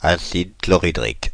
Ääntäminen
Synonyymit acide muriatique Ääntäminen Paris: IPA: [a.sid klɔ.ʁi.dʁik] France (Paris): IPA: /a.sid klɔ.ʁi.dʁik/ Haettu sana löytyi näillä lähdekielillä: ranska Käännös Substantiivit 1. hydrochloric acid Suku: m .